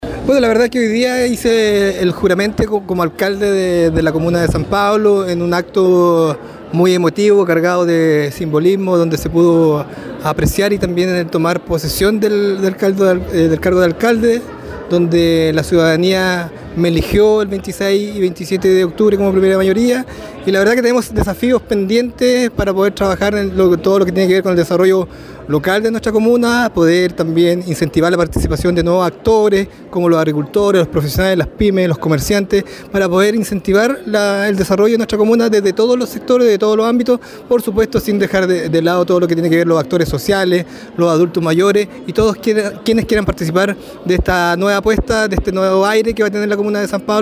En su discurso de toma de posesión, Marco Carrillo Bravo destacó su compromiso con todos los habitantes de San Pablo, enfatizando que su labor estará orientada a generar bienestar y progreso sin distinción de colores políticos.